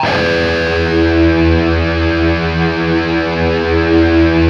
LEAD E 1 LP.wav